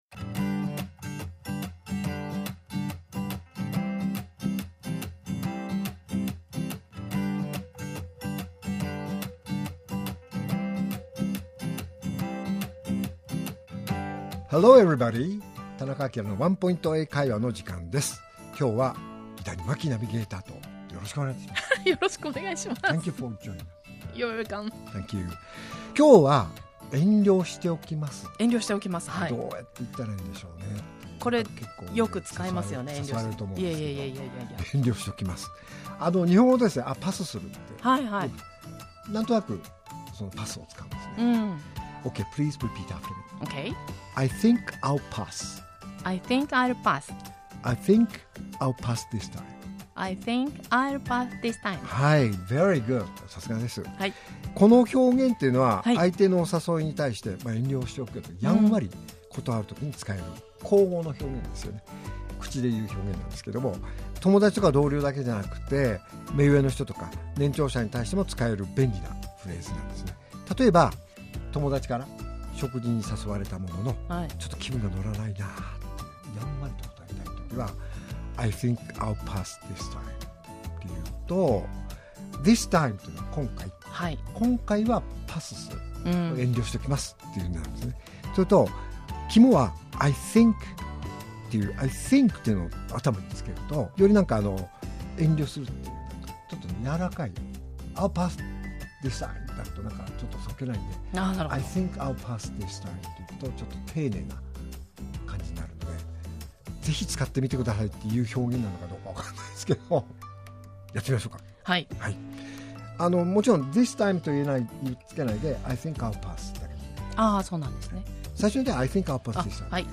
R7.4 AKILA市長のワンポイント英会話